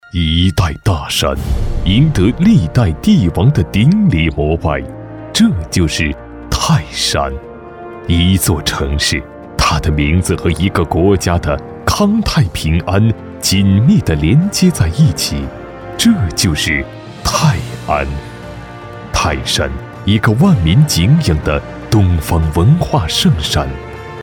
地区宣传配音男341号（大
成熟稳重 城市形象
成熟稳重男音，擅长地区宣传片配音、纪录片配音、颁奖配音、预告片配音，作品：泰山宣传片配音。